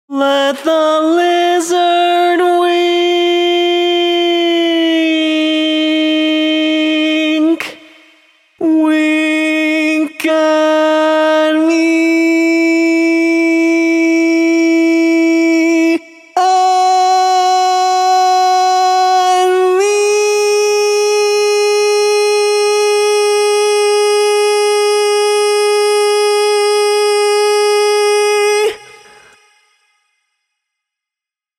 Key written in: C# Major
Type: Barbershop
Each recording below is single part only.